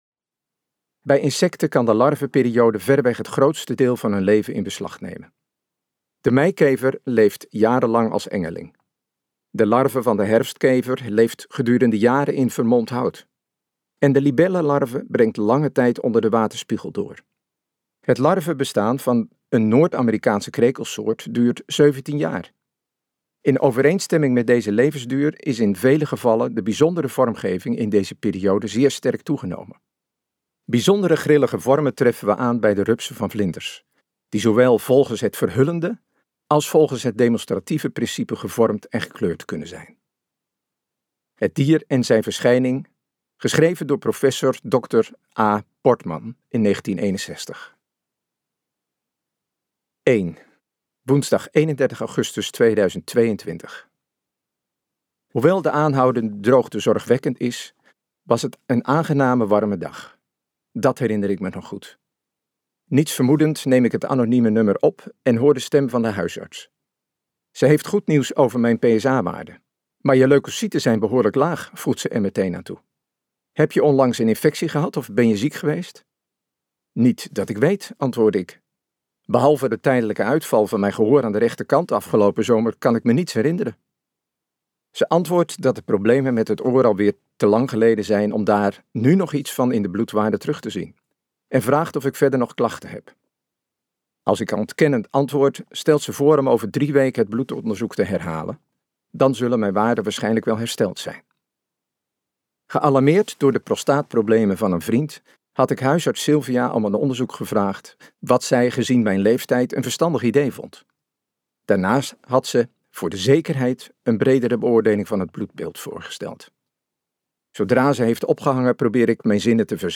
Dit kan ook een goede tijd zijn luisterboek | Ambo|Anthos Uitgevers